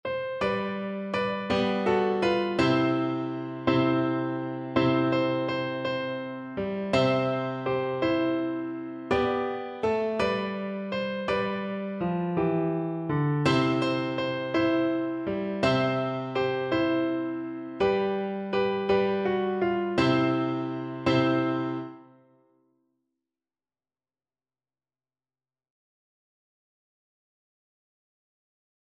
Childrens Childrens Flute Sheet Music Here we go round the Mulberry Bush
Flute
Joyfully .=c.80
6/8 (View more 6/8 Music)
C major (Sounding Pitch) (View more C major Music for Flute )